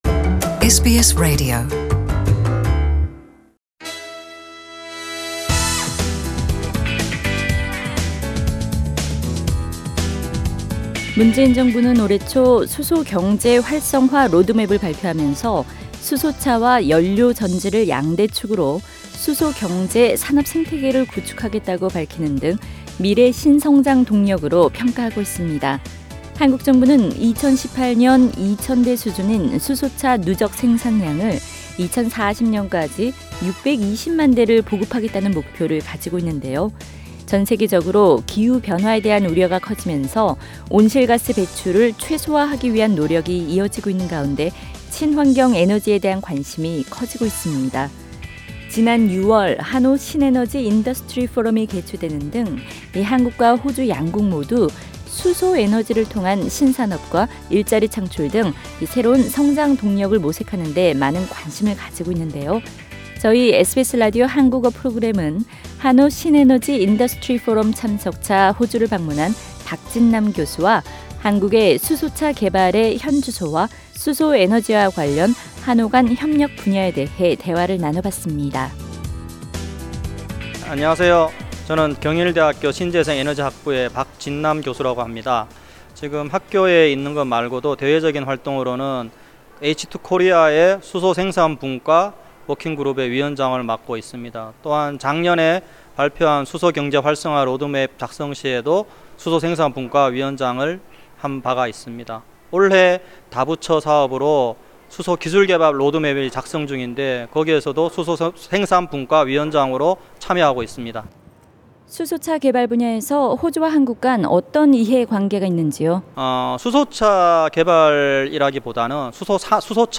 SBS Radio Korean Program exclusively conducted an interview